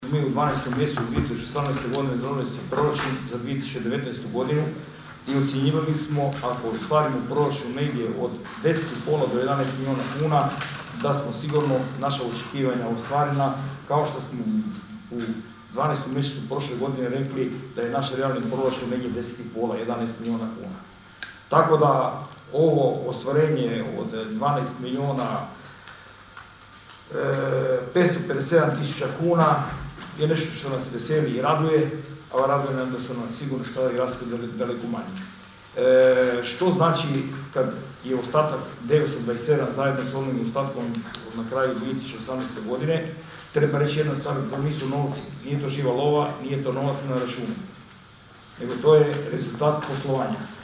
Zadovoljstvo ostvarenim proračunom izrazio je općinski načelnik Gianvlado Klarić: (